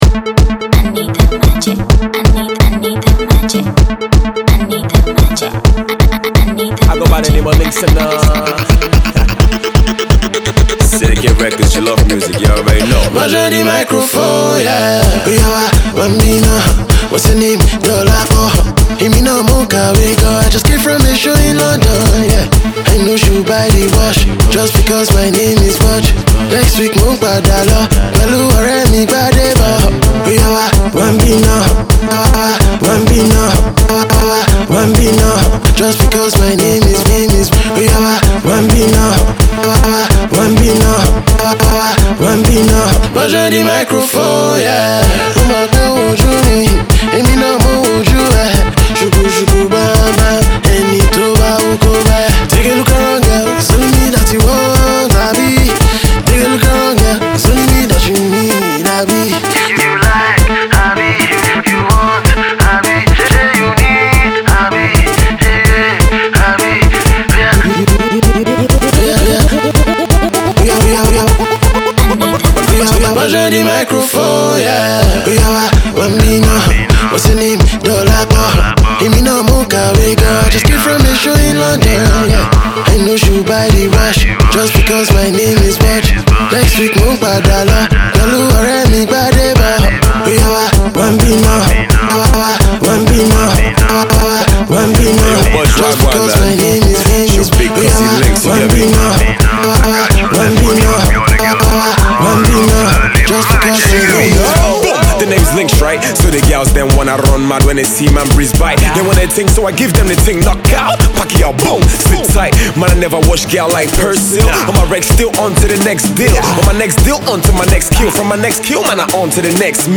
hit-making husky crooner of the Nigerian music industry